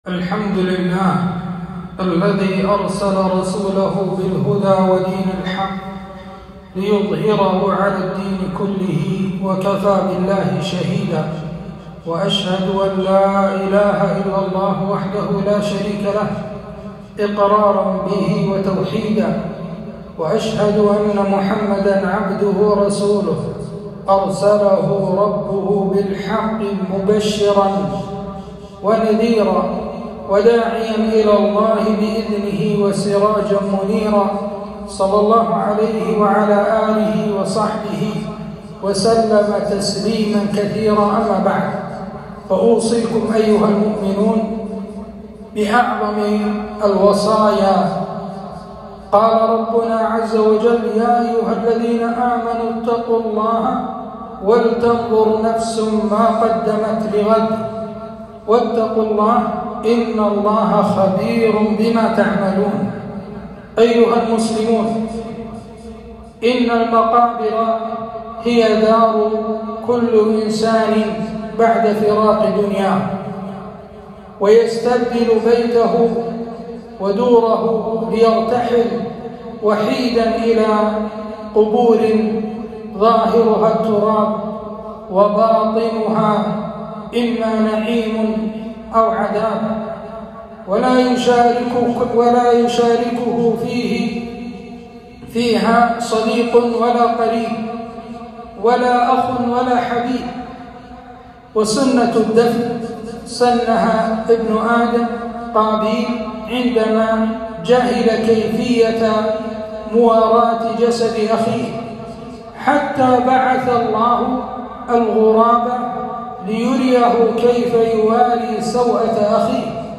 خطبة - بدع القبور أنواعها وأحكامها